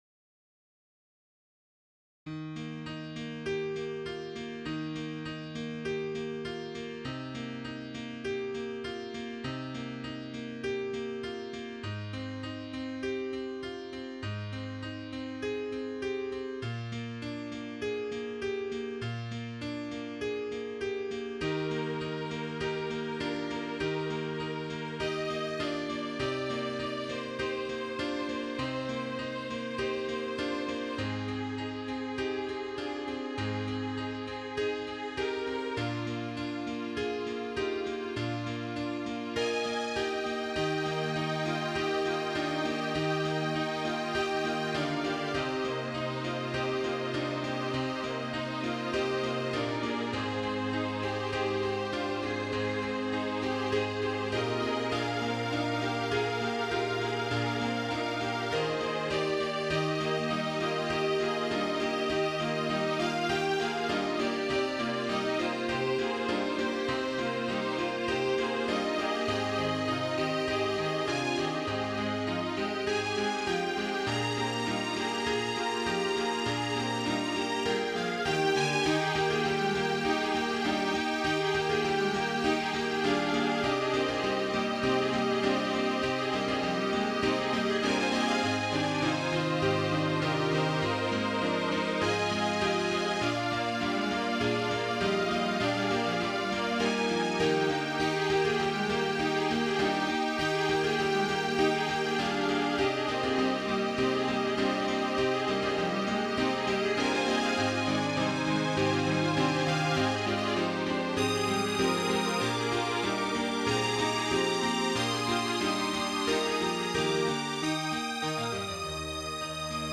Title The Promise Opus # 45 Year 0000 Duration 00:04:10 Self-Rating 3 Description One of those pieces that I kept working on and working on. That's where the odd bridge comes in. mp3 download wav download Files: mp3 wav Tags: Quartet, Piano, Strings Plays: 1458 Likes: 0